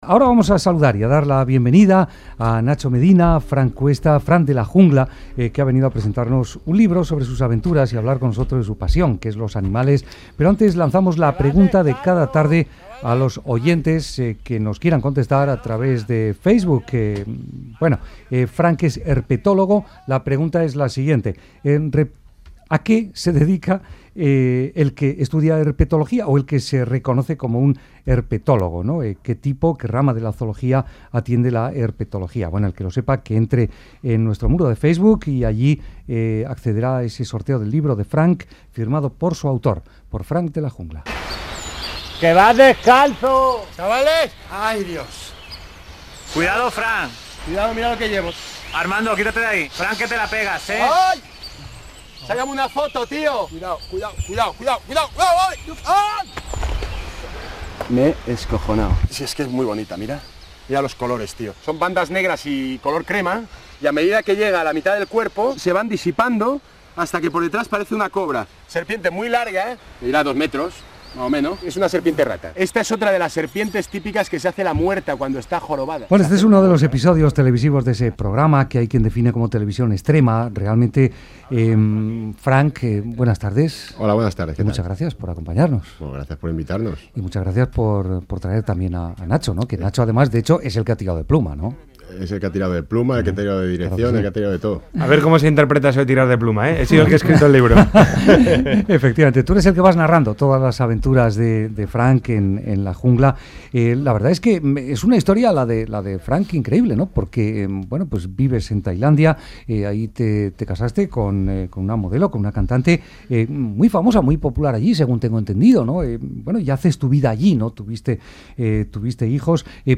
Entrevista Frank de la Jungla | libro de sus aventuras | Radio Euskadi